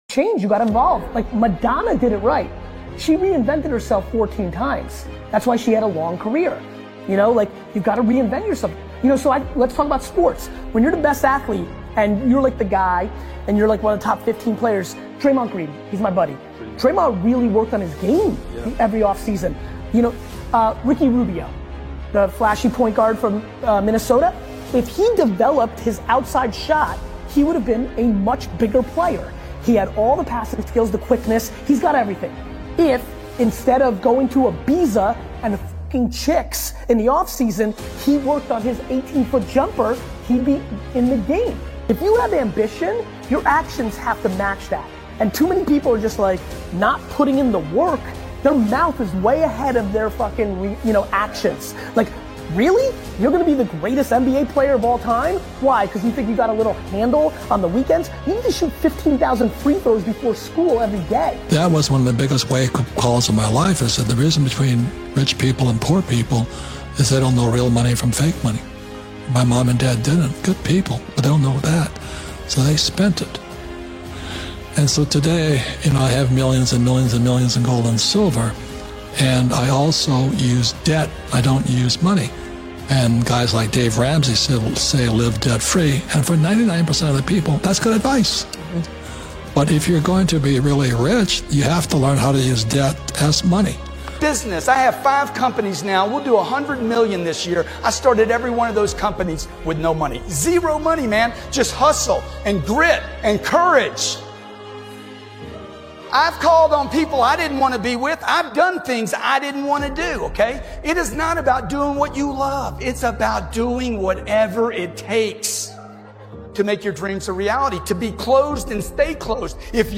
GET TO WORK | Powerful Motivational Speech 2025